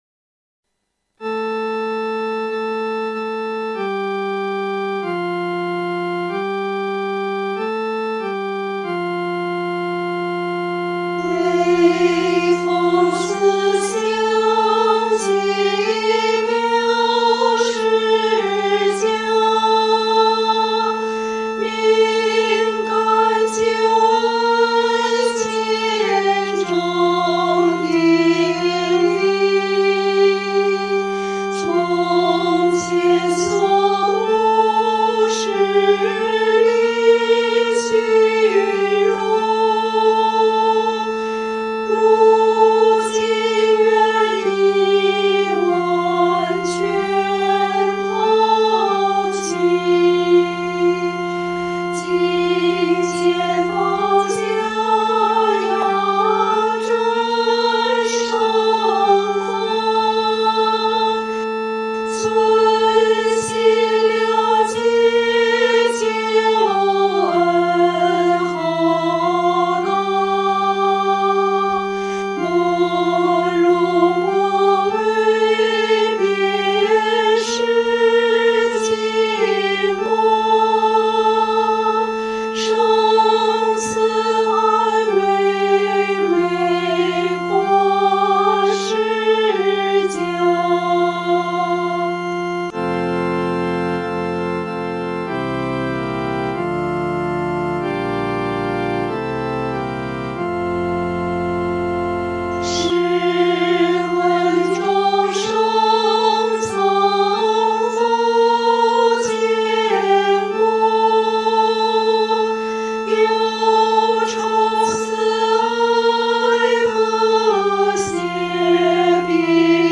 合唱
女高
见第7首注①)改写而成，庄严肃穆。